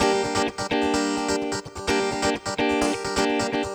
VEH3 Electric Guitar Kit 1 128BPM